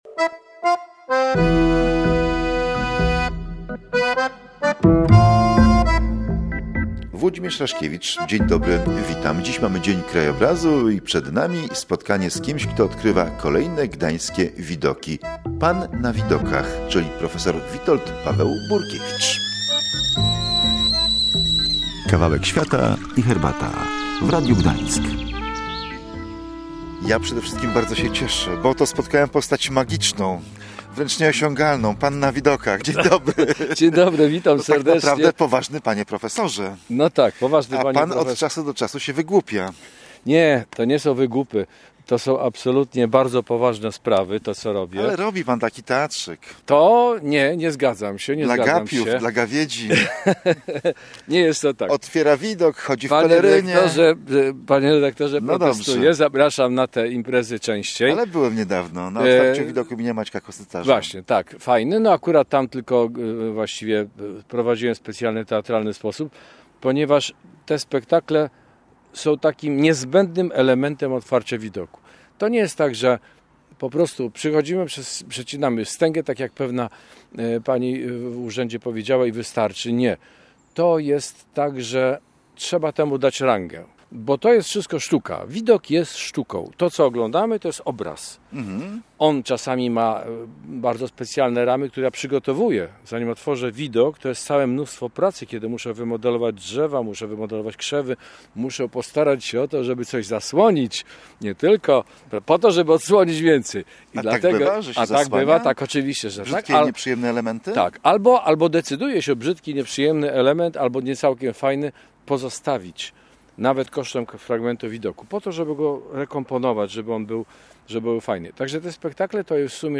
Krajobraz ma znaczenie. Rozmowa